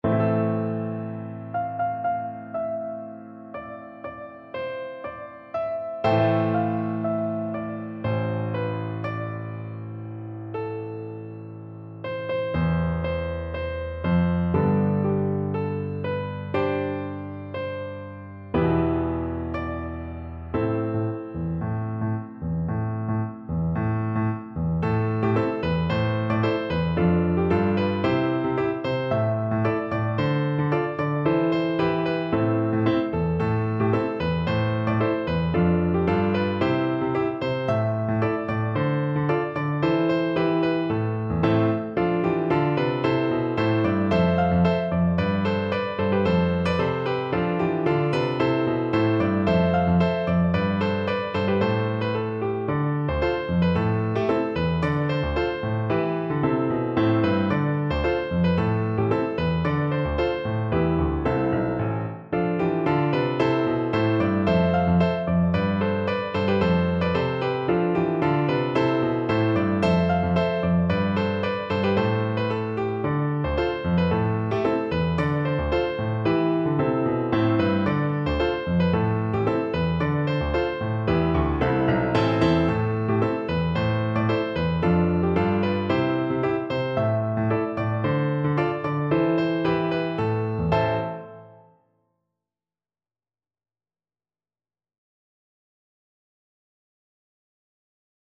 Traditional Trad. Scalerica De Oro (Jewish Sephardic) Piano version
No parts available for this pieces as it is for solo piano.
C major (Sounding Pitch) (View more C major Music for Piano )
2/4 (View more 2/4 Music)
Slow
Piano  (View more Intermediate Piano Music)
Traditional (View more Traditional Piano Music)